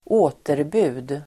Ladda ner uttalet
återbud substantiv, cancellation Uttal: [²'å:terbu:d] Böjningar: återbudet, återbud, återbuden Synonymer: avbeställning Definition: meddelande att man inte kan komma Exempel: lämna återbud (notify of a cancellation)